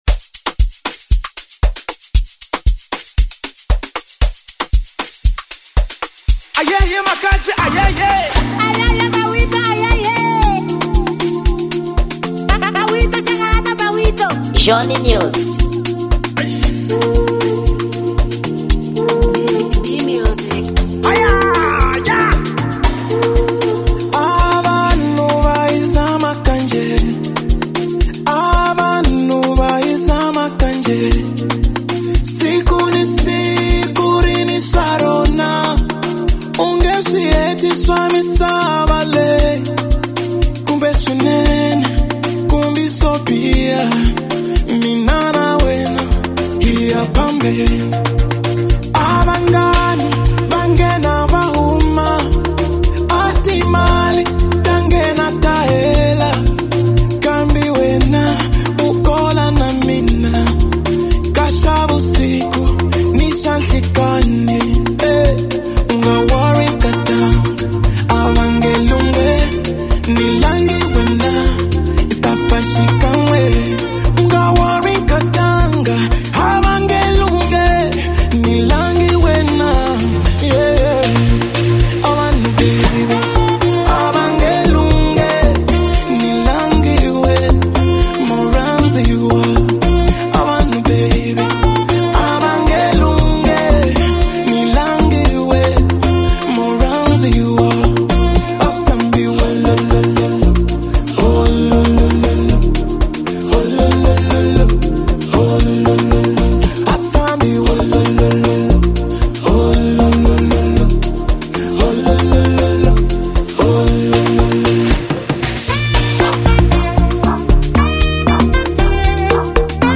Gênero: Marrabenta